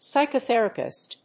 /ˌsaɪkʌˈθɛrʌpɪst(米国英語), ˌsaɪkʌˈθerʌpɪst(英国英語)/